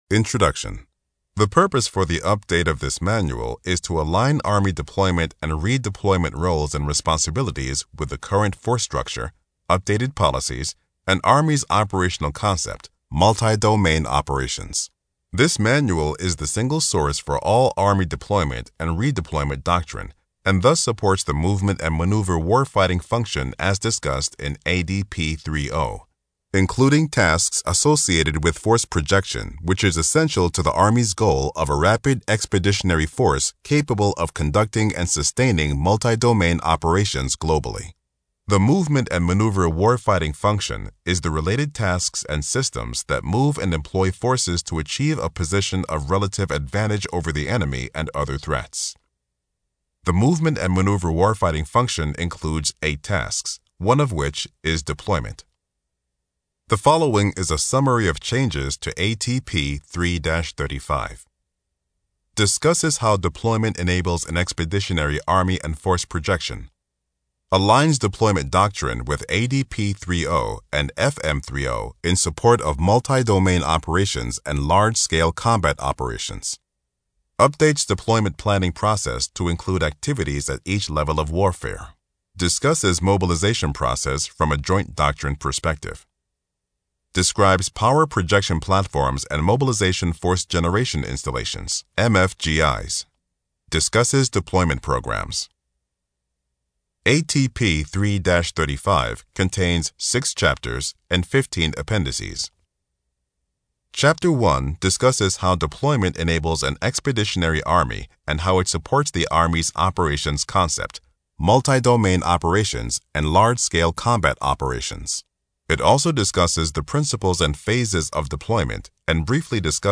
Army Doctrine Audiobook Download Page
It has been abridged to meet the requirements of the audiobook format.